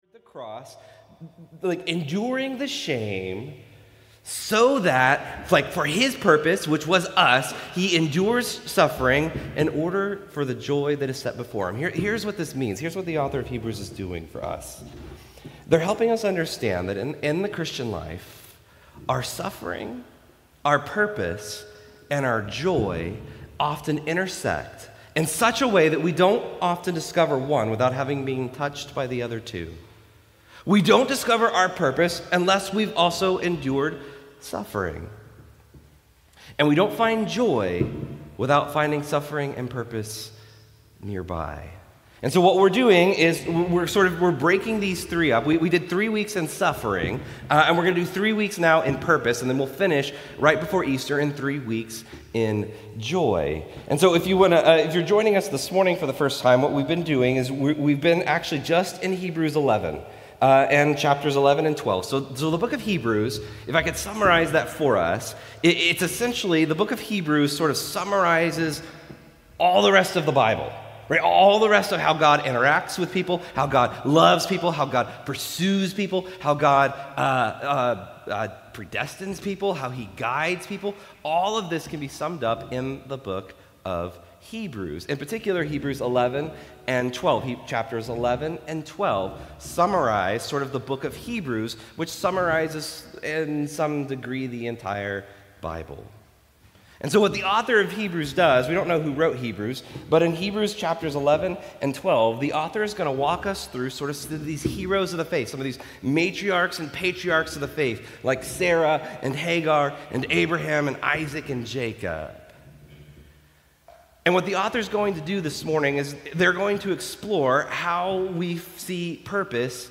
Restore Houston Church Sermons Why Am I Here?